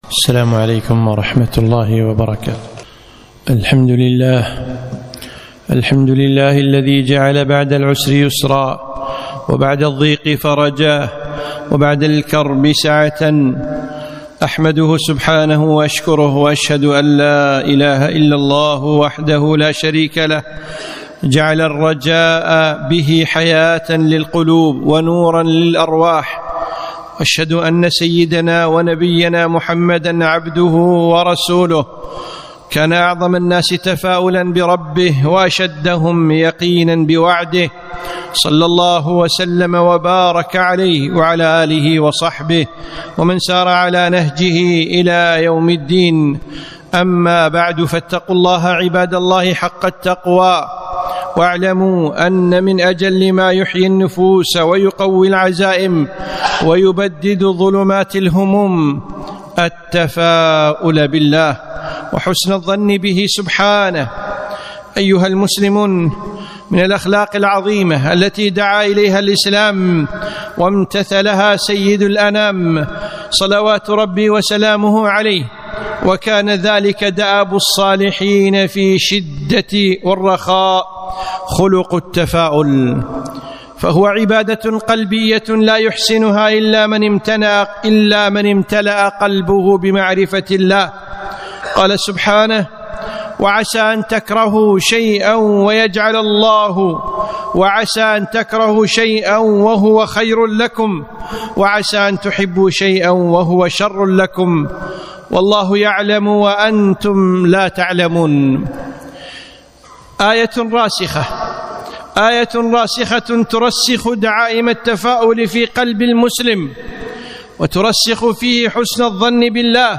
خطبة - تفاءلوا